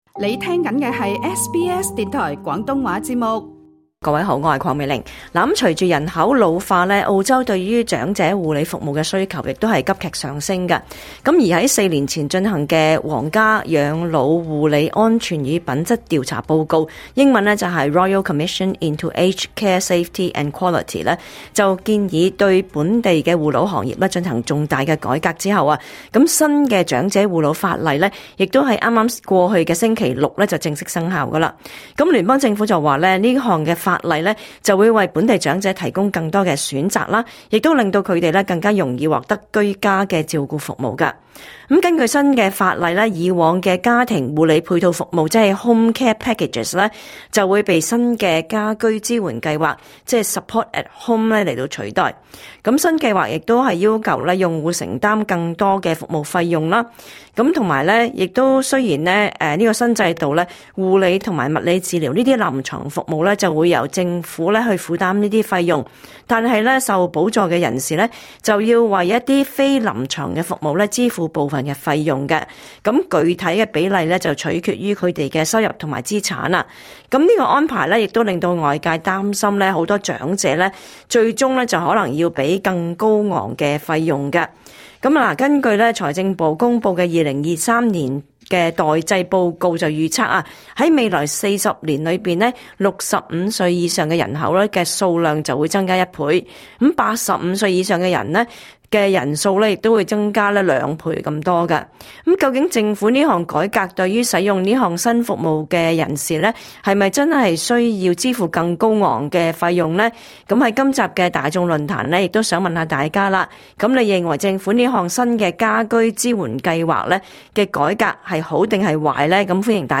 【業界人士解答疑難】新「家居支援計劃」你知多少？